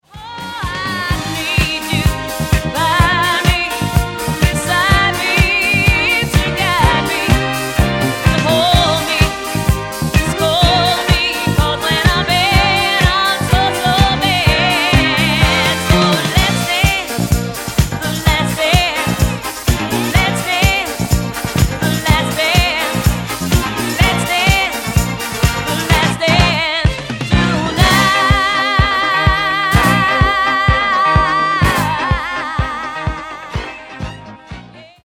Genere:   Disco| Funky | Soul |